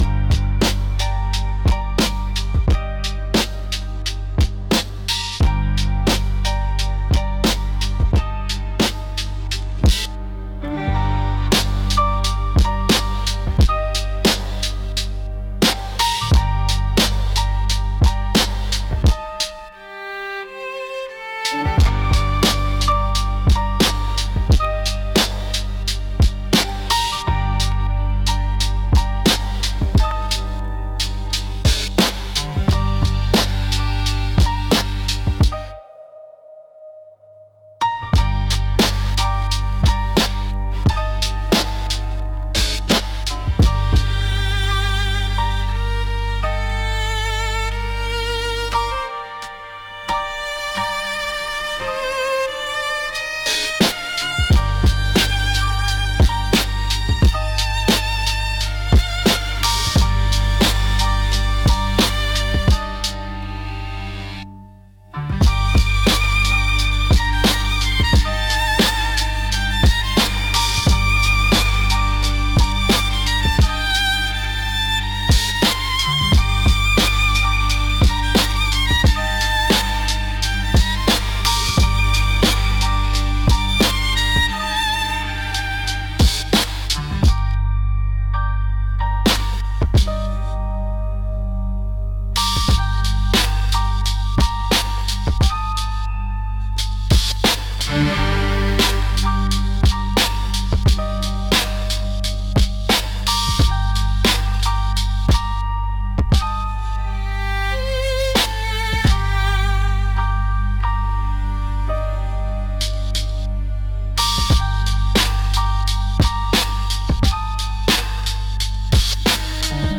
Instrumental - Whispers in the Dark - 3.27